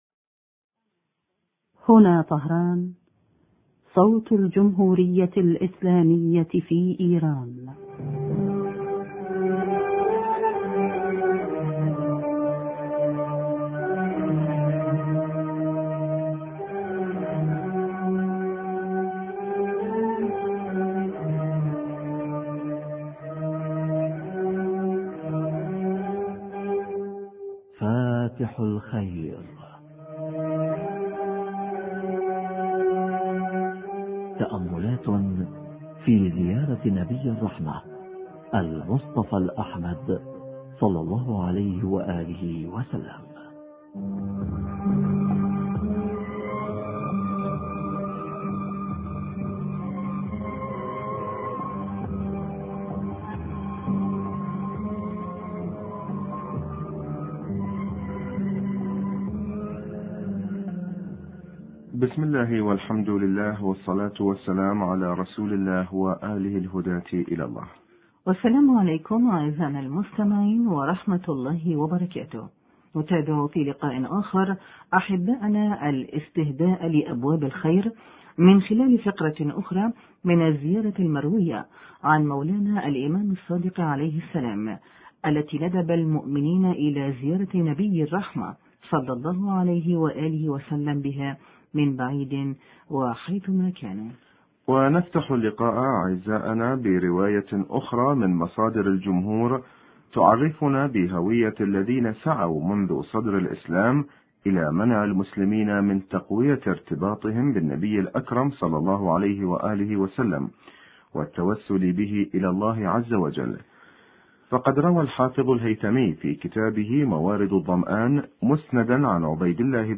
هوية المانعين من الارتباط بالنبي الاكرم(ص) شرح فقرة: نبيك ونذيرك وأمينك ومكينك ونجيك... حوار
اللقاء الهاتفي